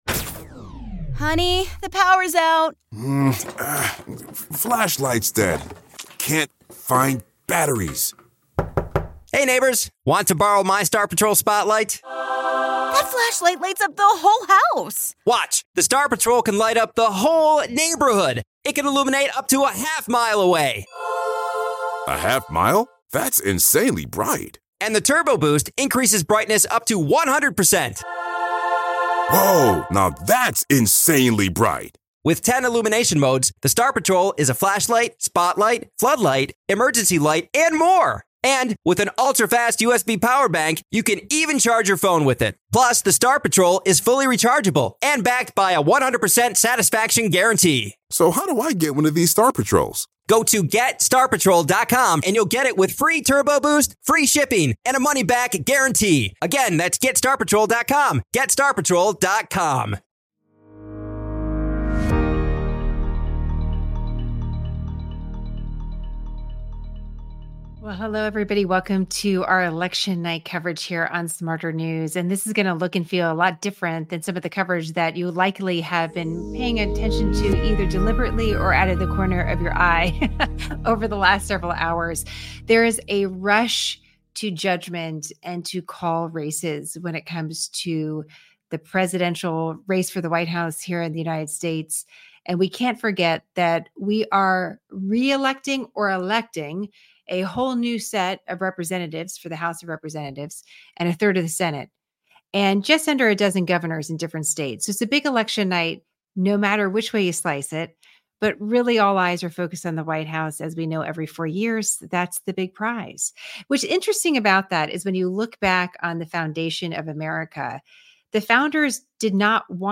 SmartHERNews Jenna Lee Education, News 5 • 615 Ratings 🗓 6 November 2024 ⏱ 33 minutes 🔗 Recording | iTunes | RSS 🧾 Download transcript Summary Our SmartHER 8 p.m. central broadcast on Election Night 2024.